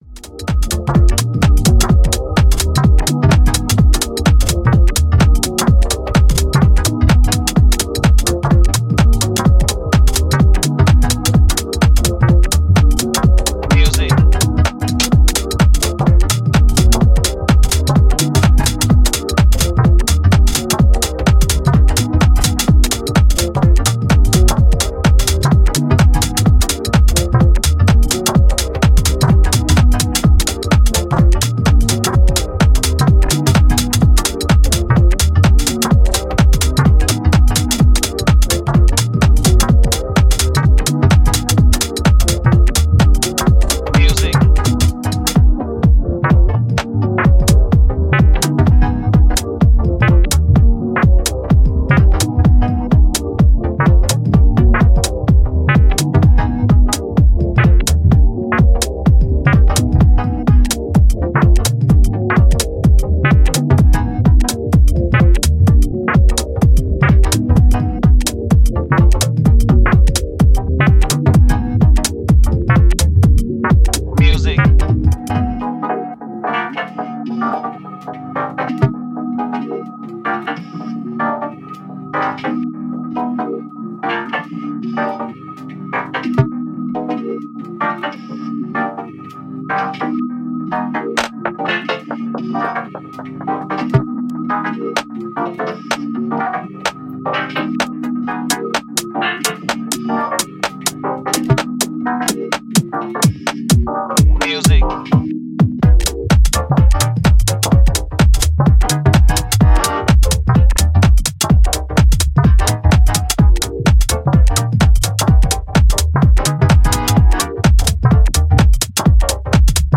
a powerful minimalistic and deep trip
full of groove and swinging hats